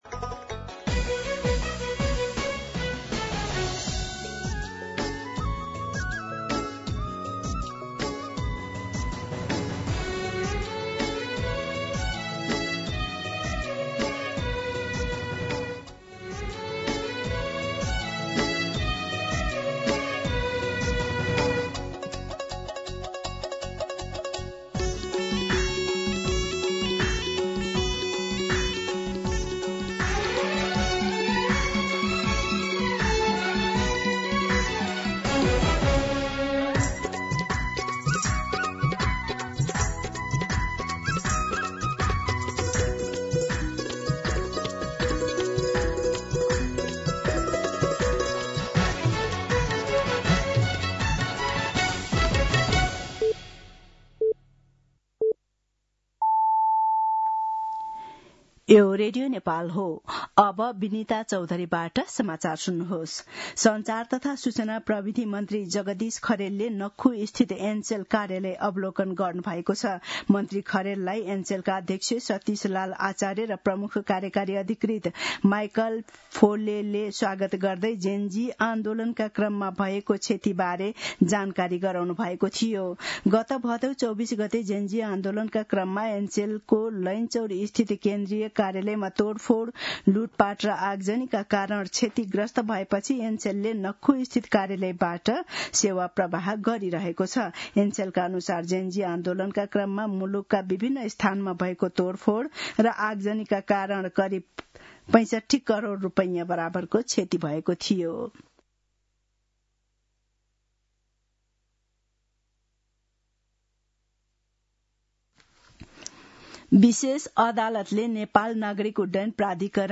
दिउँसो १ बजेको नेपाली समाचार : २२ मंसिर , २०८२